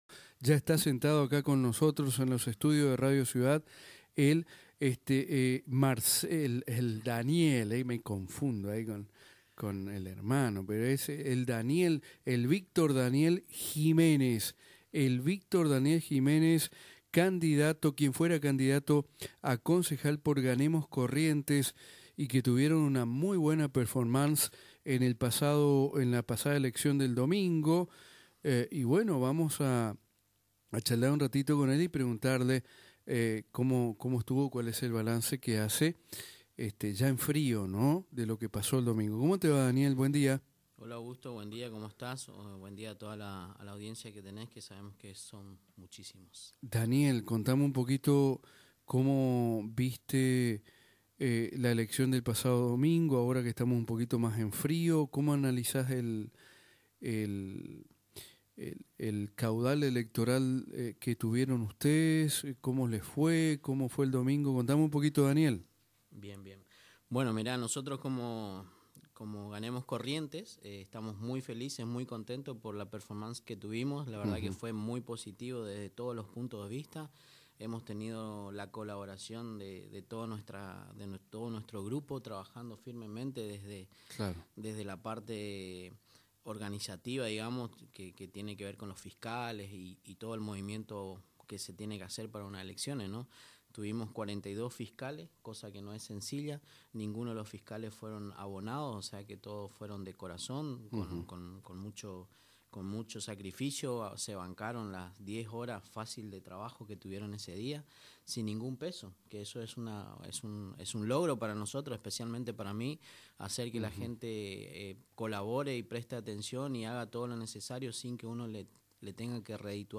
brindó una entrevista a radio ciudad en la que manifestó que el partido va seguir adelante